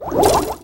Bubble.wav